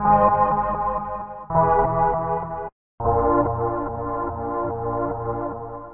描述：Cd切割，从CD上切下，并用Sawcutter 1,2或其它切割，用FX处理，标准化。